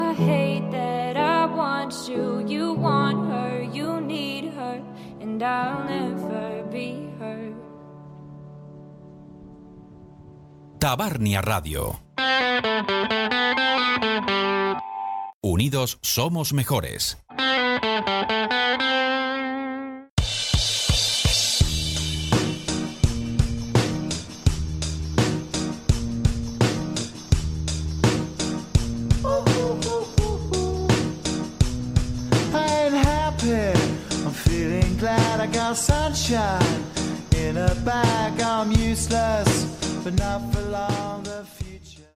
Tema musical, indicatiu i tema musical